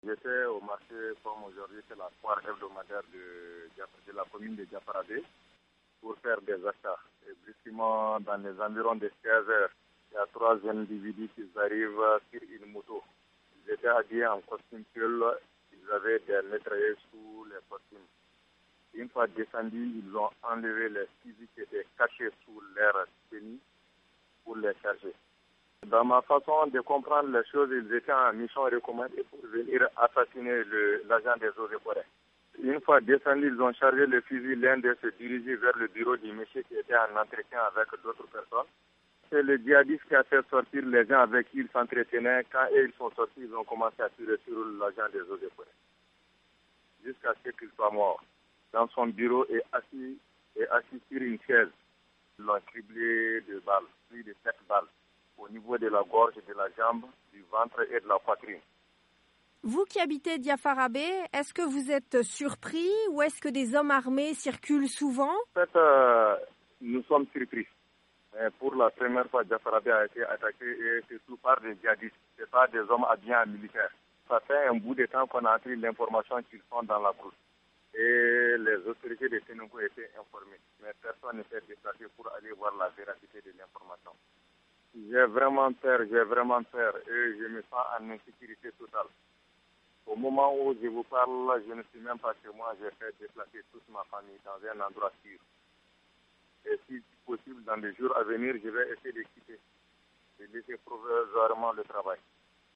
Un habitant de Diafarabé joint